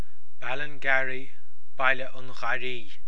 Pronunciation Audio File